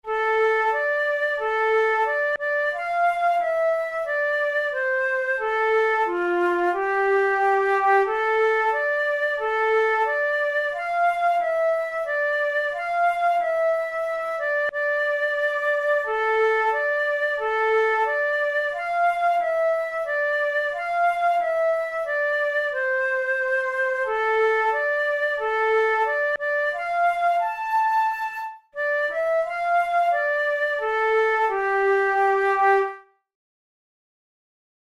Traditional Appalachian carol
Written in a minor key, its qualities of pensiveness make it one of today's most best loved Christmas tunes.
Categories: Christmas carols Traditional/Folk Difficulty: easy